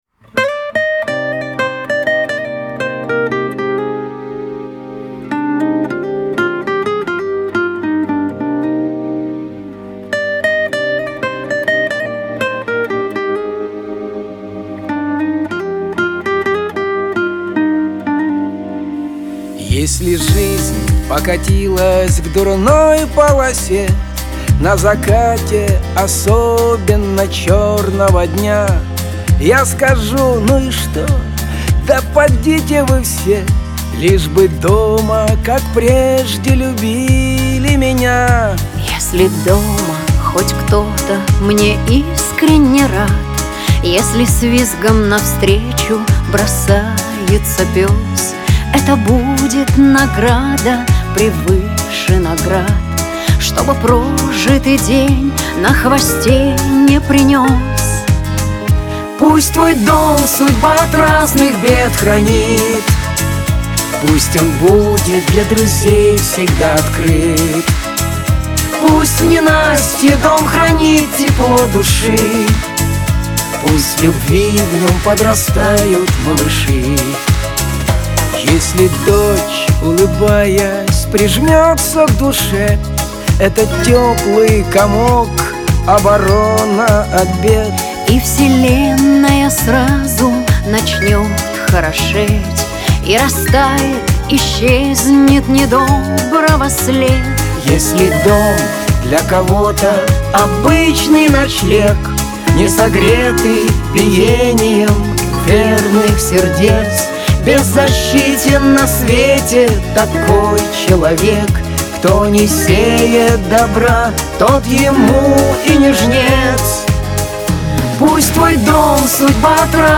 Трек размещён в разделе Русские песни / Эстрада.